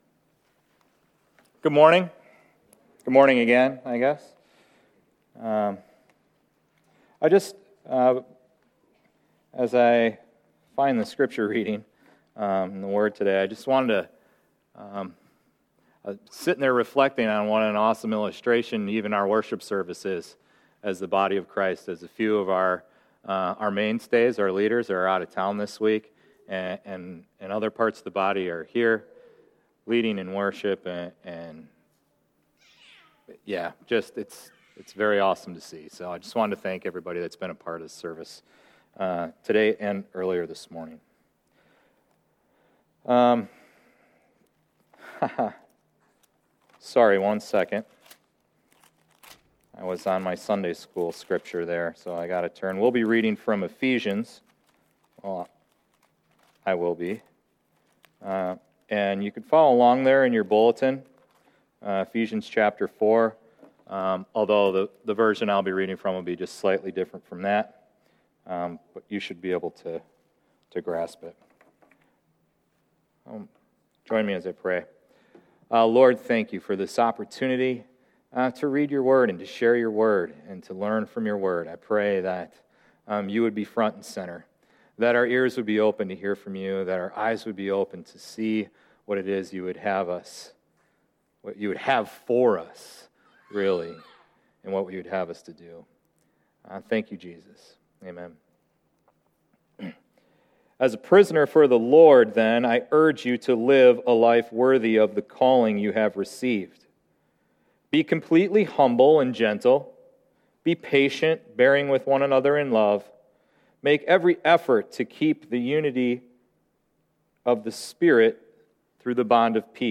January 12, 2014 Ligaments of Love Passage: Ephesians 4:1-16 Service Type: Sunday Morning Service Ligaments of Love Ephesians 4:1-16 Introduction: (Therefore) in light of what Paul has written so far about our position in Christ.